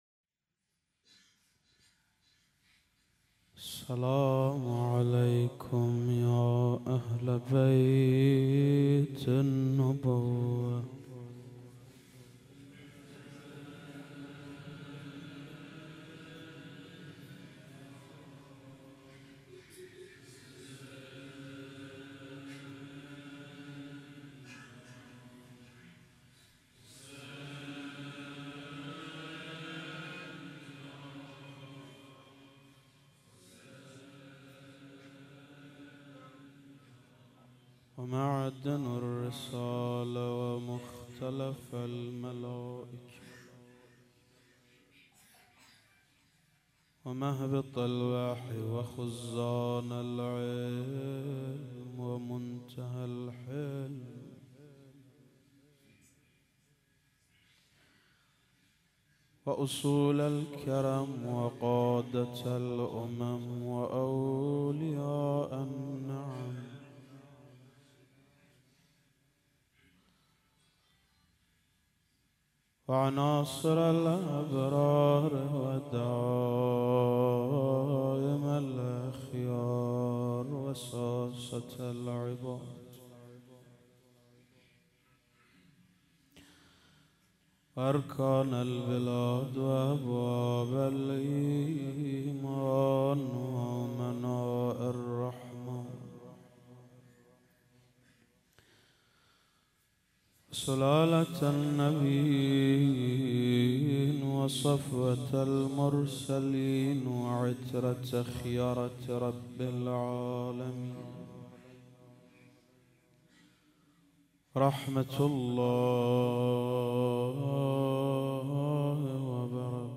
هیئت خدام العباس(ع)اهواز